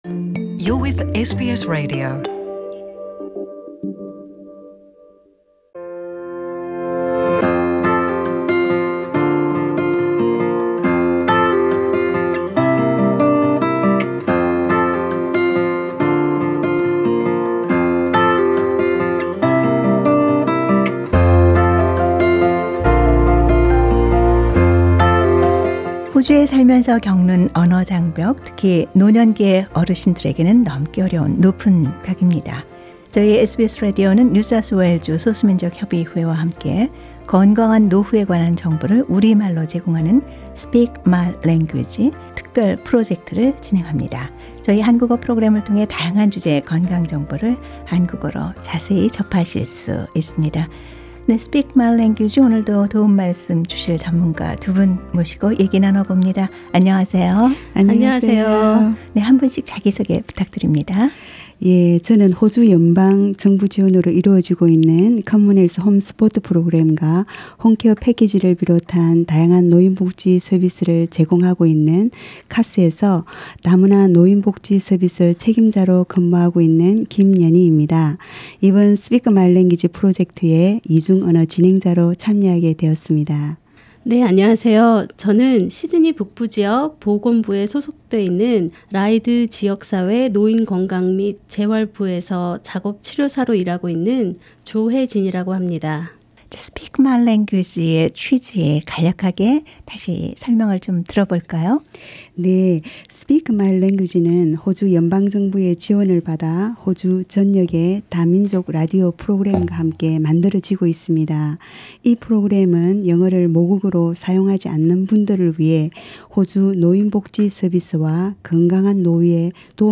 Speak My Language: conversations about ageing well Source: Ethnic Communities Council NSW